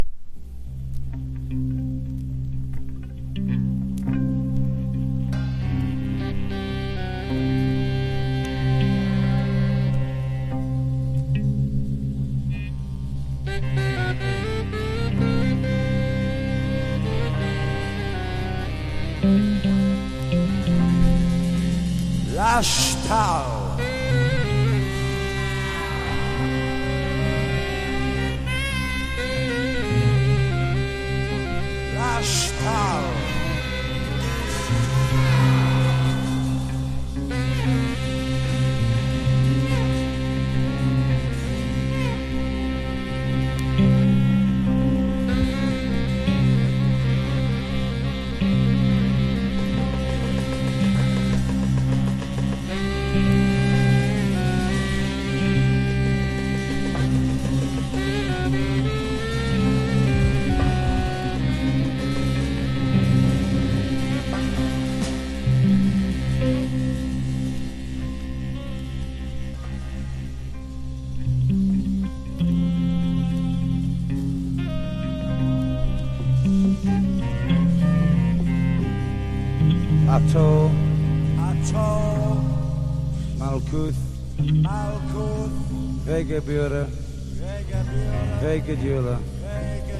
1. 70'S ROCK >
肝心の音はサイケデリックでハードかつヘヴィーなオルガンが印象的な1枚。
PSYCHEDELIC / JAZZ / PROGRESSIVE